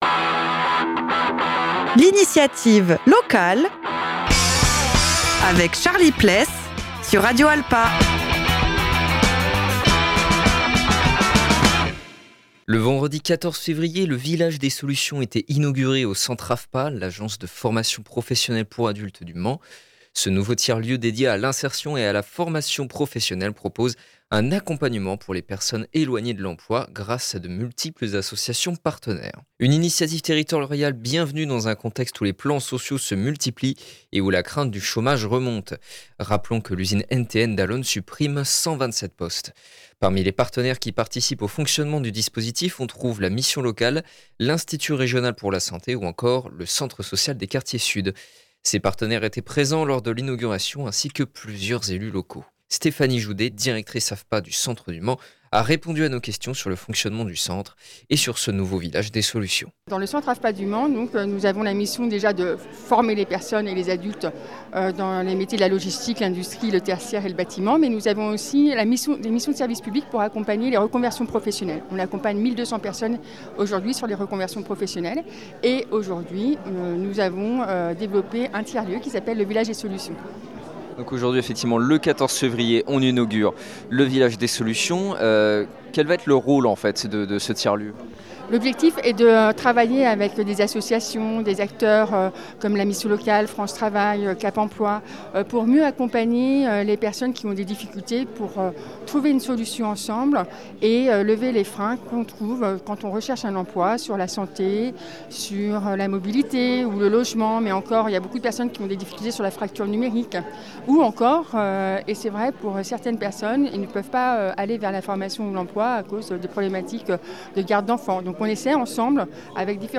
Le vendredi 14 février, le Village des solutions était inauguré au centre AFPA, l’agence de formation professionnelle pour adultes du Mans.